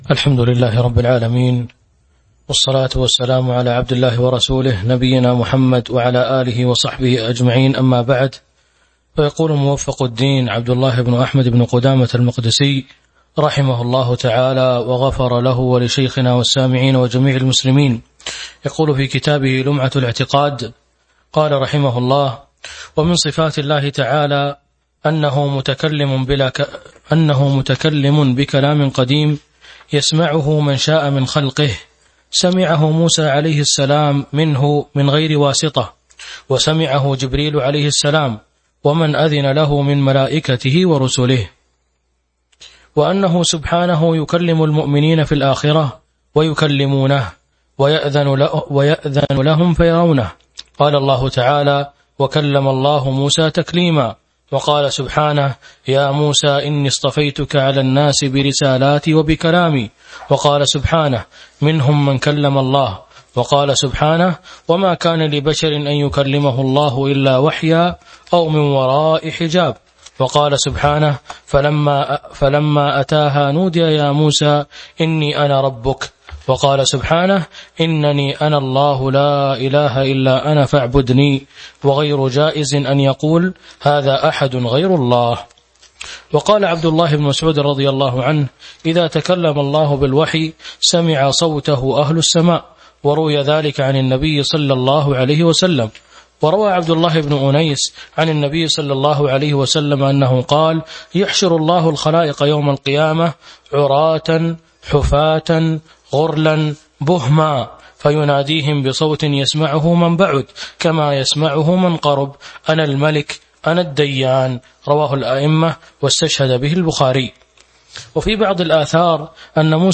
تاريخ النشر ١٩ ذو الحجة ١٤٤٢ هـ المكان: المسجد النبوي الشيخ